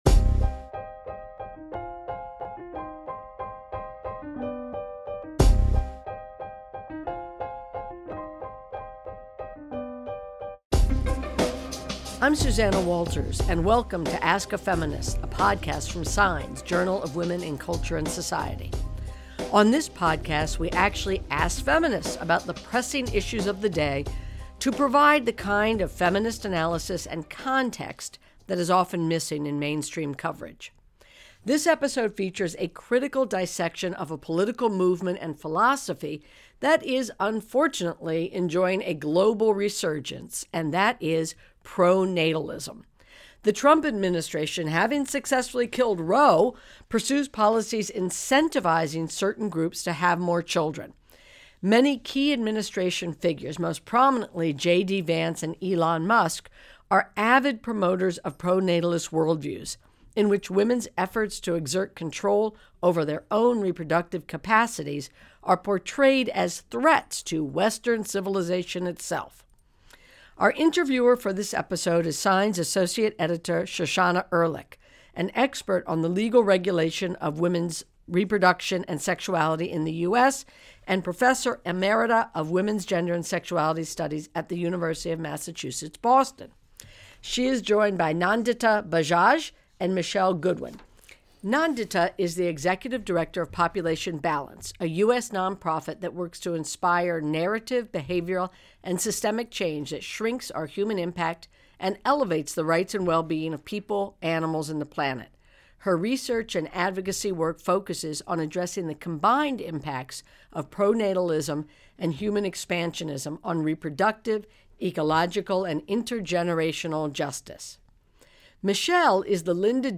The following conversation took place through SquadCast in December 2025.